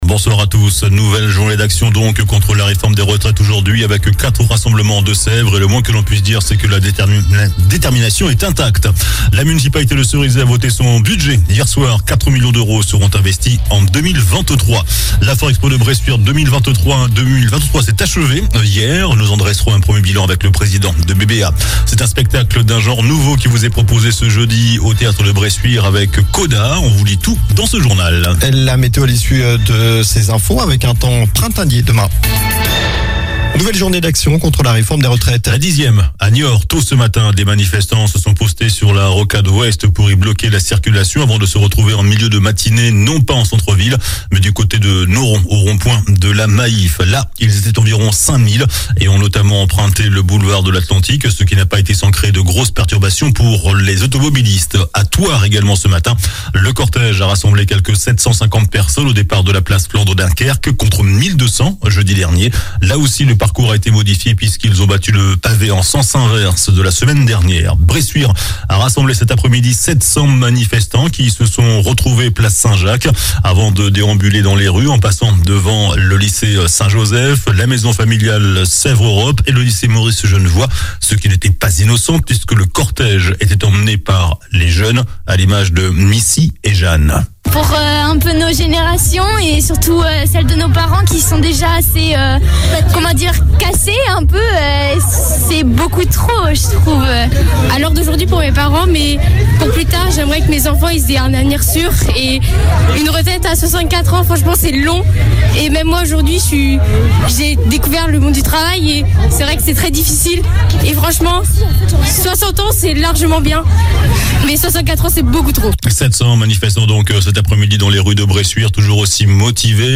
JOURNAL DU MARDI 28 MARS ( SOIR )